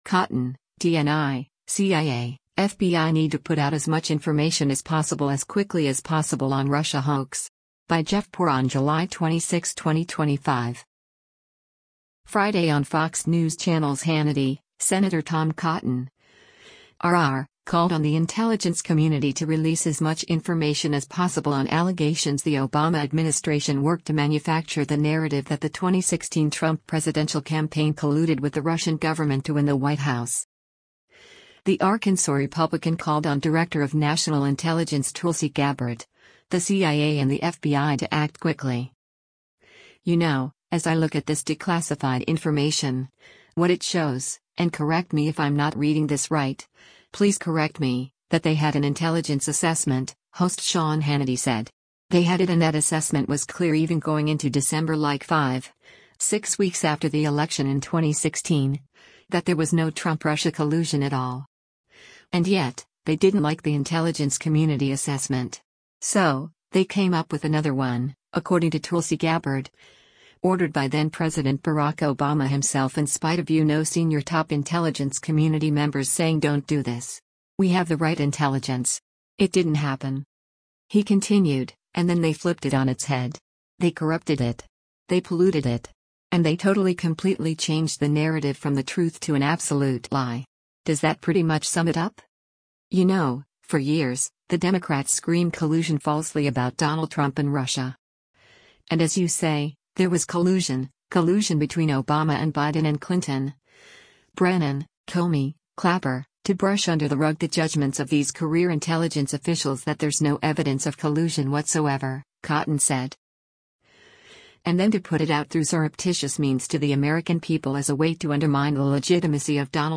Friday on Fox News Channel’s “Hannity,” Sen. Tom Cotton (R-AR), called on the intelligence community to release “as much information as possible” on allegations the Obama administration worked to manufacture the narrative that the 2016 Trump presidential campaign colluded with the Russian government to win the White House.